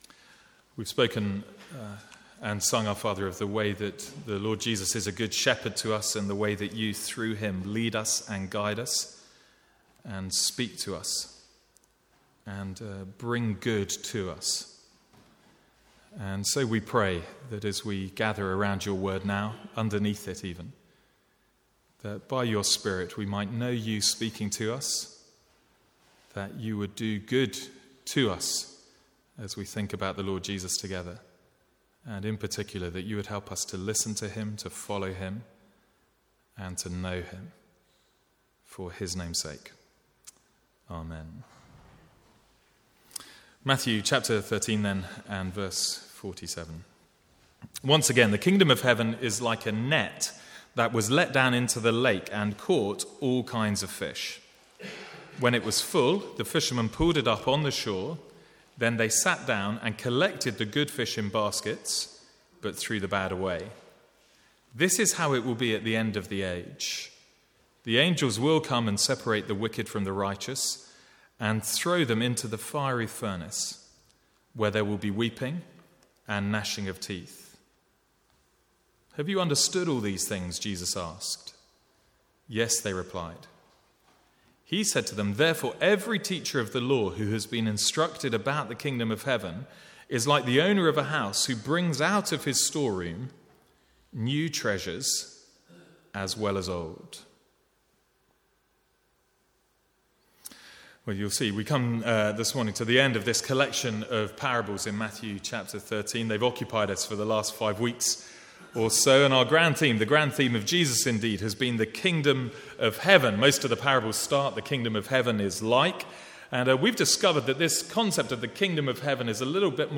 Sermons | St Andrews Free Church
From the Sunday morning series in Matthew (recorded 15/6/14).